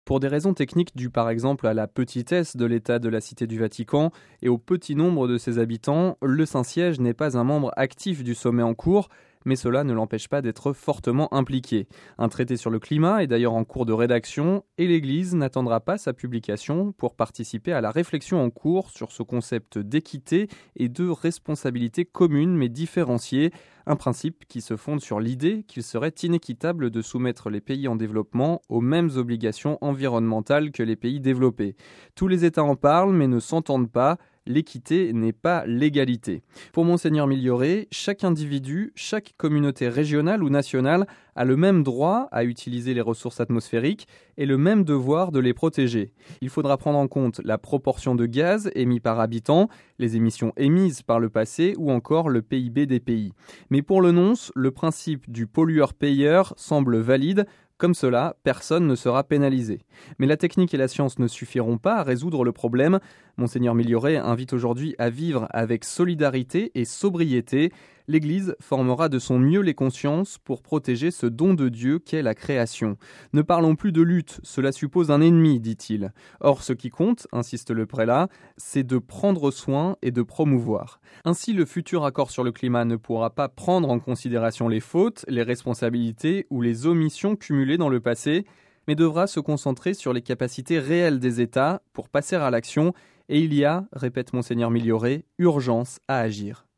Le compte-rendu